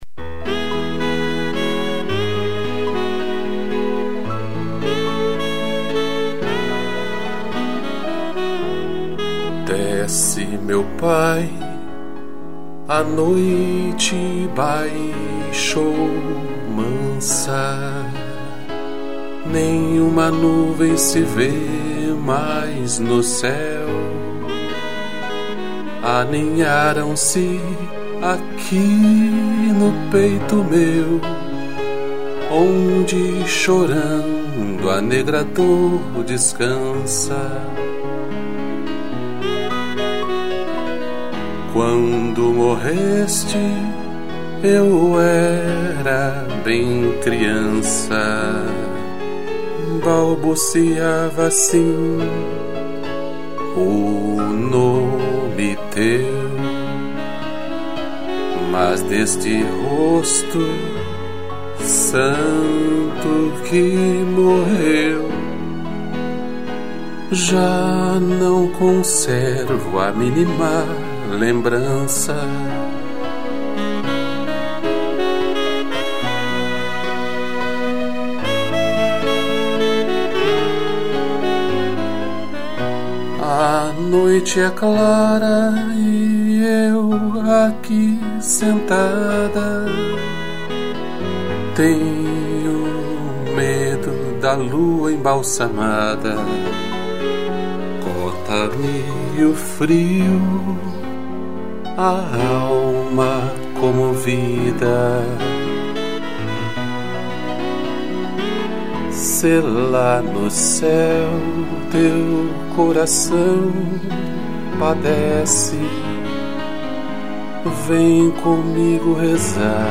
2 pianos, sax e strings